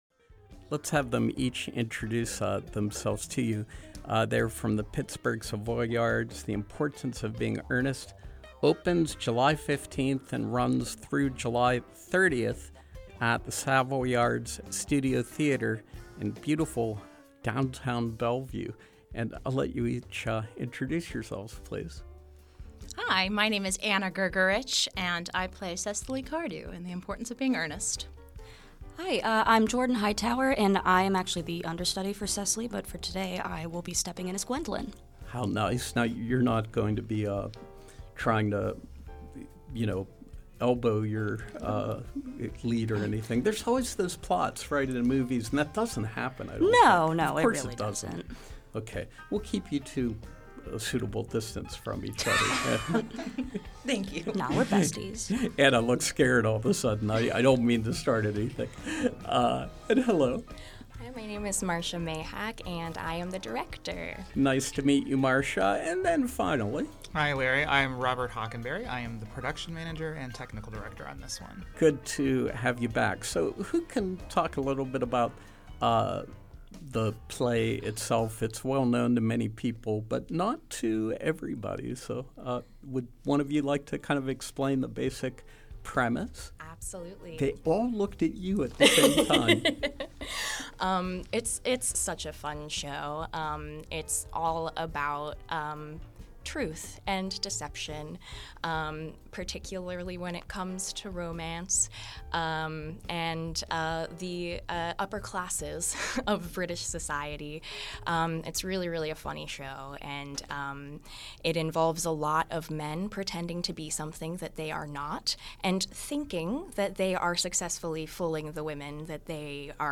In Studio Pop-Up: The Importance of Being Earnest, Pittsburgh Savoyards